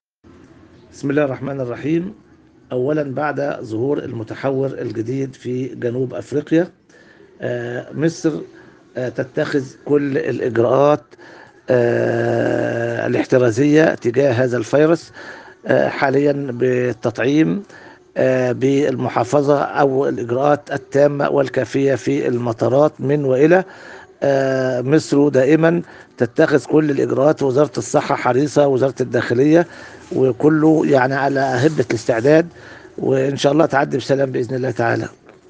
الحوار الصحفي التالي